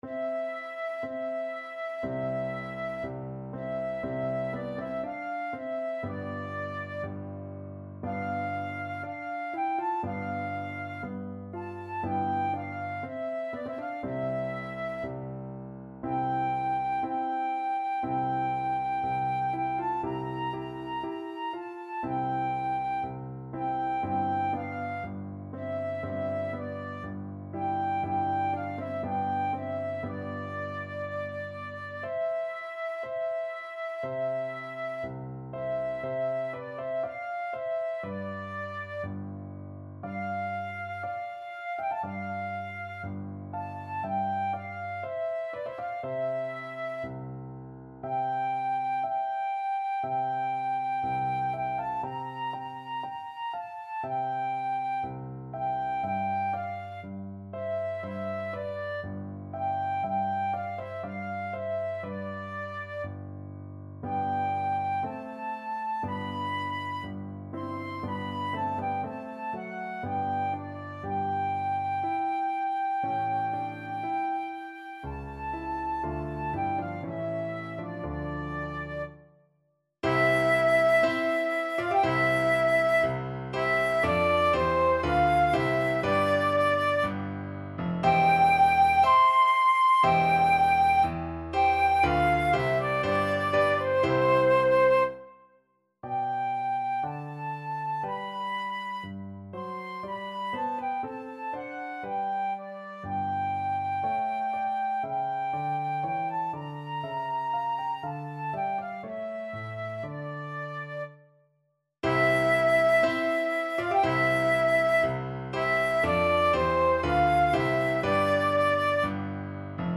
Classical Handel, George Frideric Funeral March from Saul Flute version
4/4 (View more 4/4 Music)
C major (Sounding Pitch) (View more C major Music for Flute )
Slow =c.60
Flute  (View more Easy Flute Music)
Classical (View more Classical Flute Music)